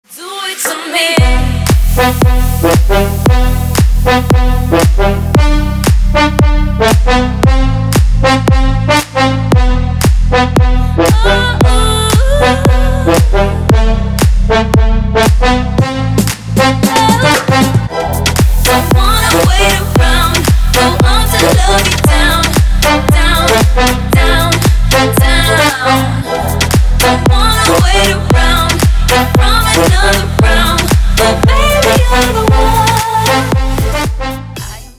• Качество: 320, Stereo
громкие
женский голос
Electronic
EDM
Стиль: deep house